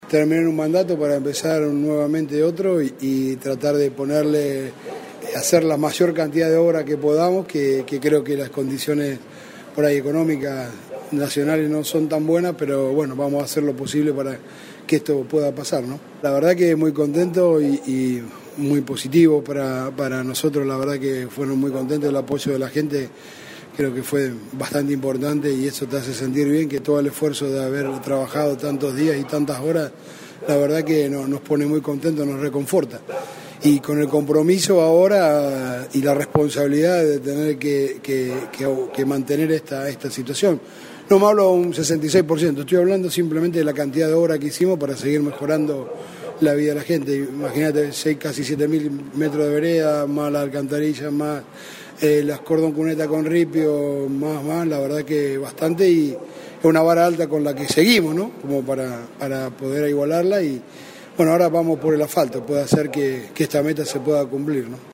Este miércoles a la tarde se realizó en Villa Minetti el acto de asunción de autoridades comunales, donde el actual presidente Gabriel Gentili puso formalmente en marcha su segundo mandato.
El acto tuvo lugar en el Salón de Usos Múltiples de la localidad con la participación de integrantes de la flamante comisión comunal, representantes de instituciones y vecinos.
En primer turno, Gabriel Gentili dejó sus impresiones de los primeros dos años al frente de la Comuna: